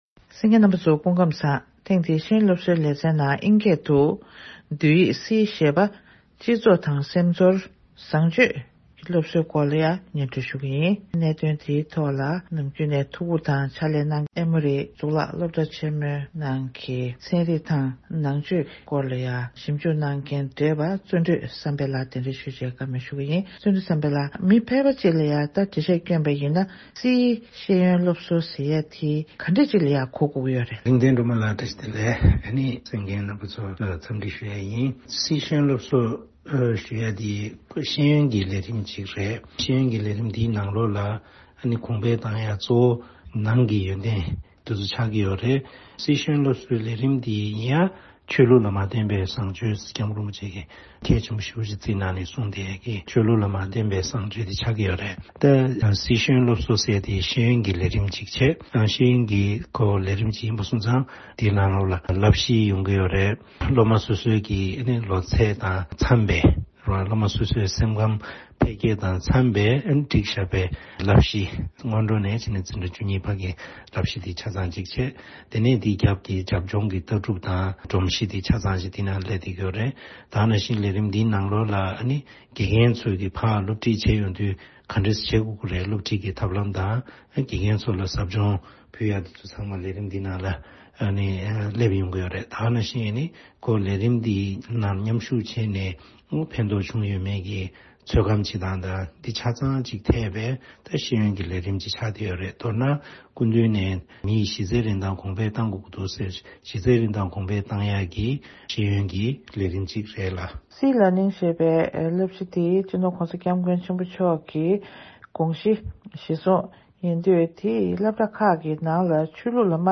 གནས་འདྲི་ཞུས་པ་ཞིག་གསན་གྱི་རེད།